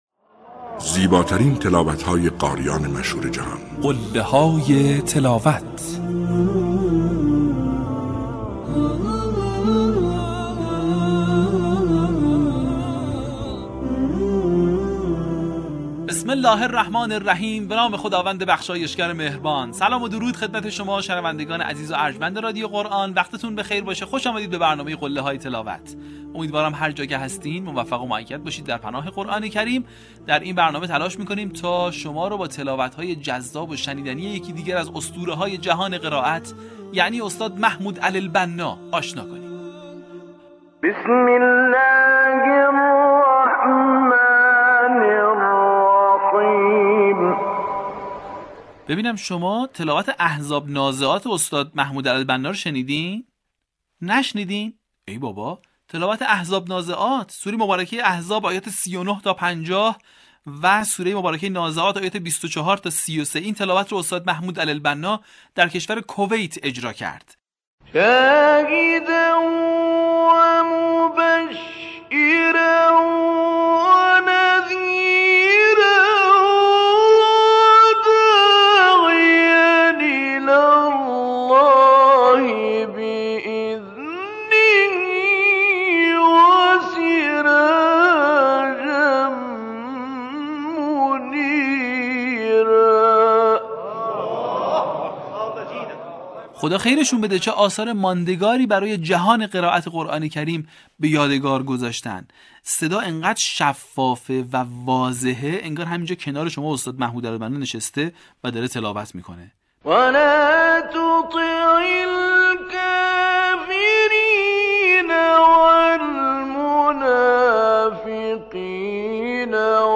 در قسمت چهاردهم، فرازهای شنیدنی از تلاوت‌های به‌یاد ماندنی استاد محمود علی البنا را می‌شنوید.
برچسب ها: شیخ محمود علی البنا ، قله های تلاوت ، فراز تلاوت ، تلاوت تقلیدی ، تلاوت ماندگار